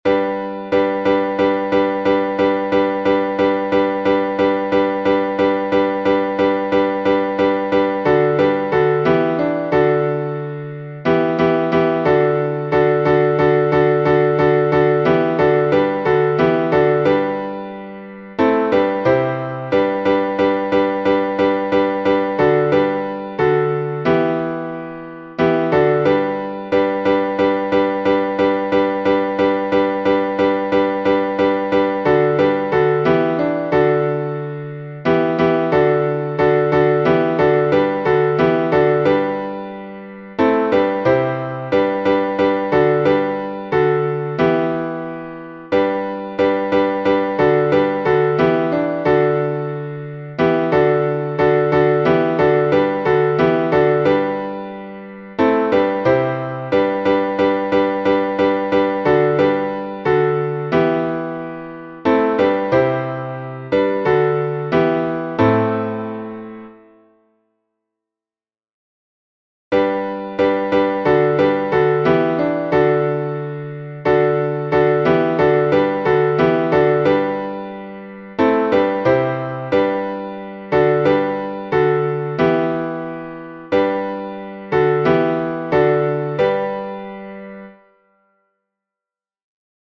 Малый знаменный распев, глас 6